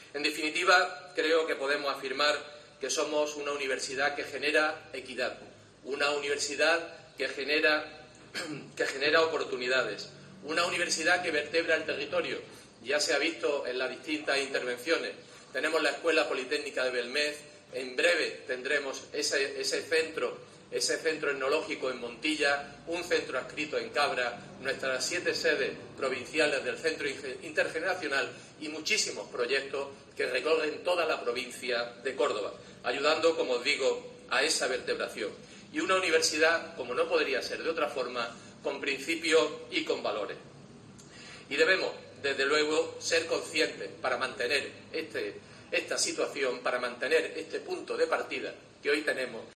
Escucha al rector de la UCO, José Carlos Gómez Villamandos, en el acto de celebración del 50 aniversario
AUDIO: Escucha al rector de la UCO, José Carlos Gómez Villamandos, en el acto de celebración del 50 aniversario